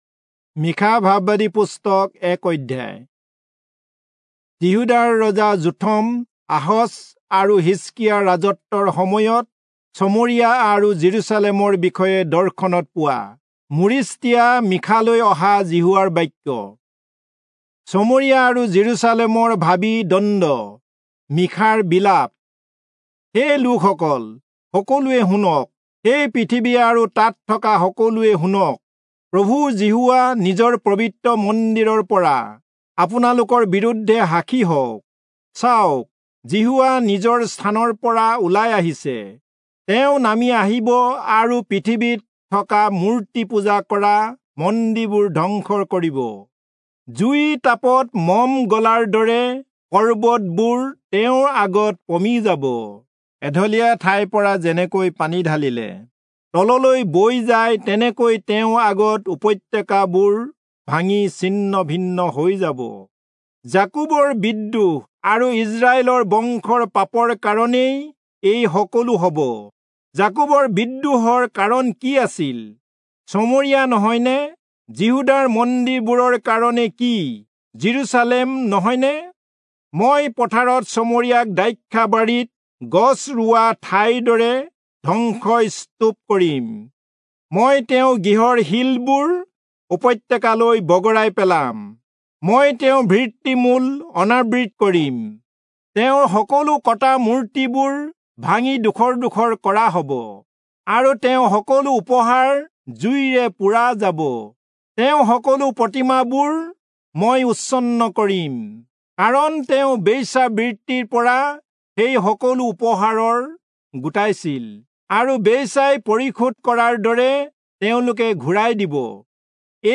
Assamese Audio Bible - Micah 4 in Irvas bible version